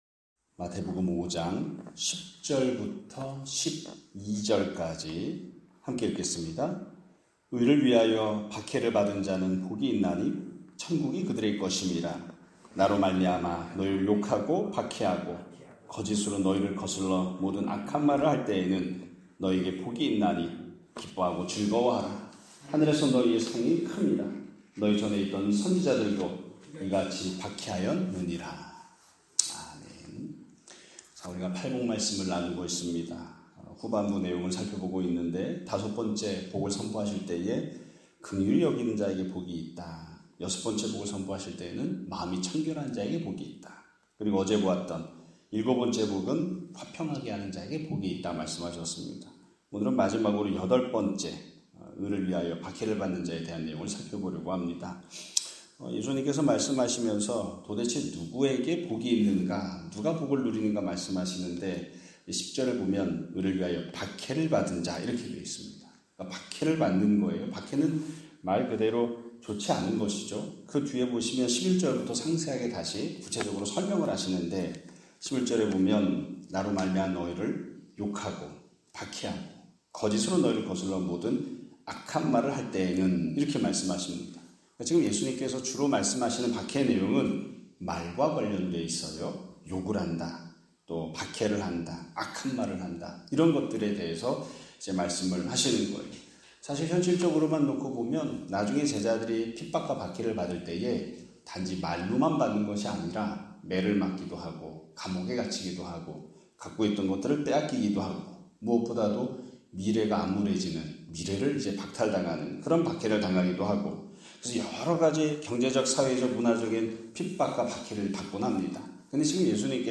2025년 5월 16일(금요일) <아침예배> 설교입니다.